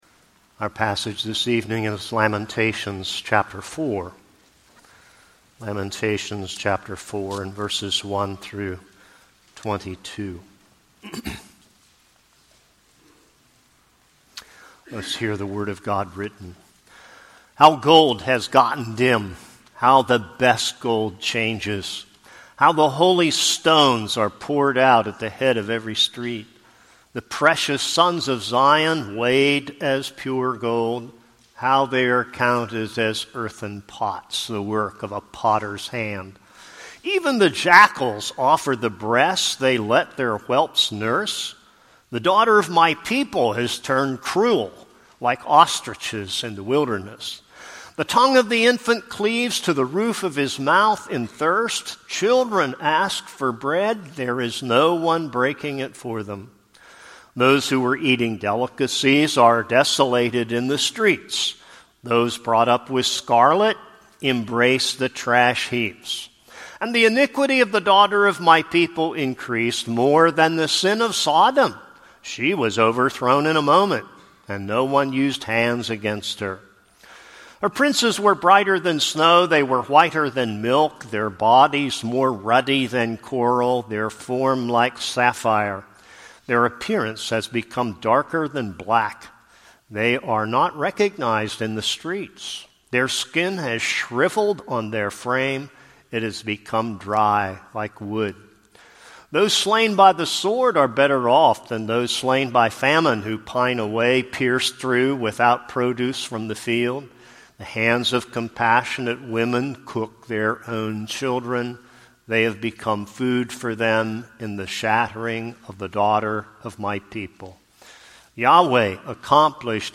This is a sermon on Lamentations 4.